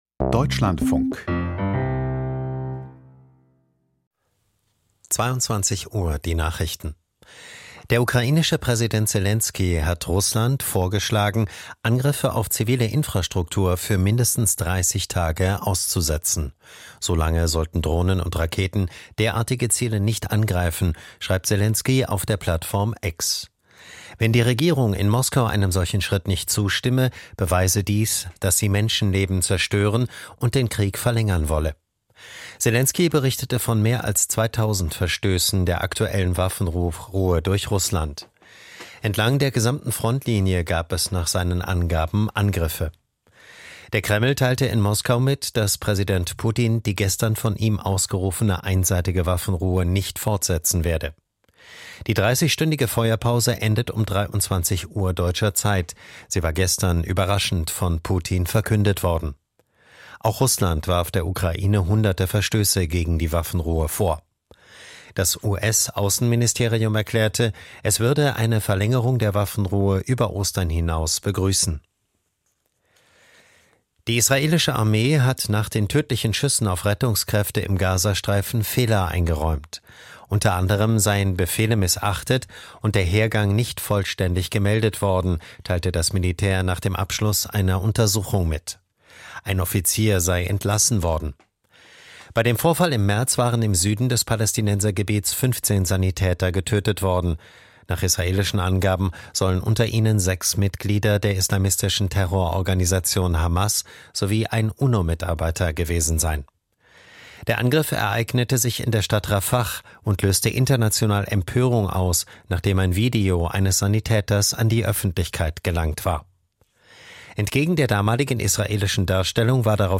Die Deutschlandfunk-Nachrichten vom 20.04.2025, 22:00 Uhr